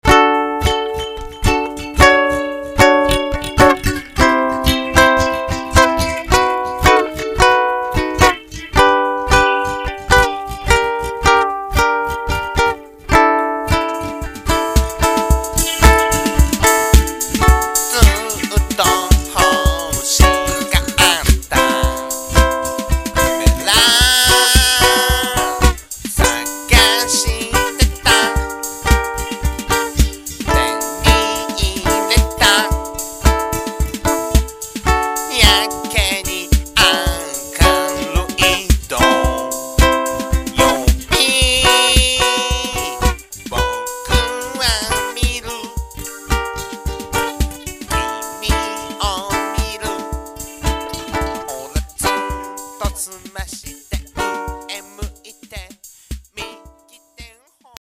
ウクレレサウンド
歌と演奏はナヘナヘソフトサイケユニット（笑）